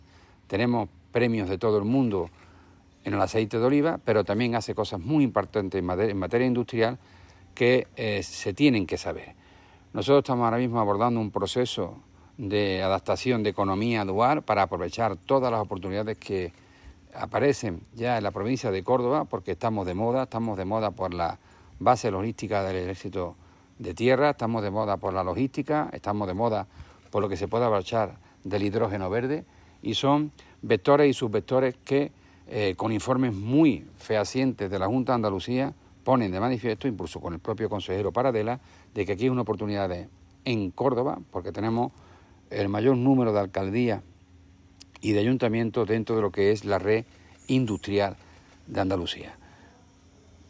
SONIDO.-Visita-Cabra.-Salvador-Fuentes-2.mp3